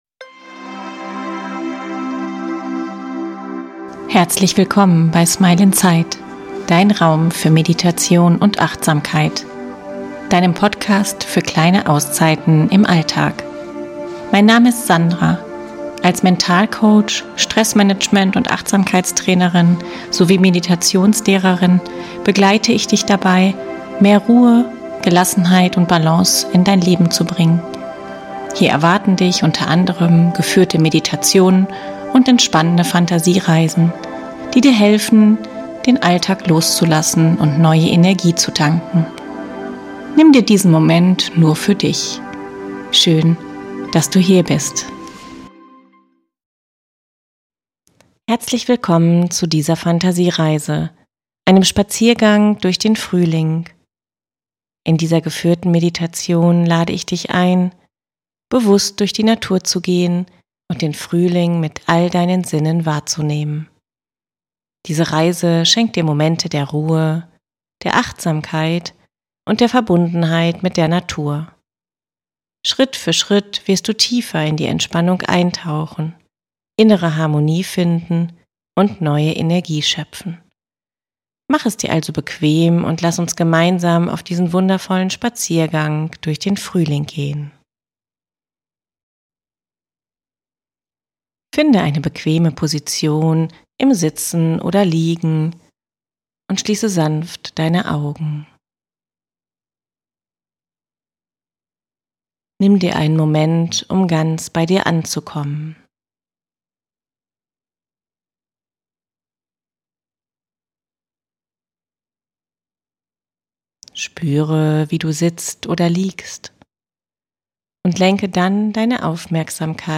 In dieser geführten Meditation lade ich dich ein, bewusst durch die Natur zu gehen und den Frühling mit all deinen Sinnen wahrzunehmen.